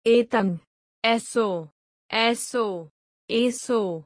Above are three words: etam, eso#1, eso#2, eso#3
Which eso sounds better?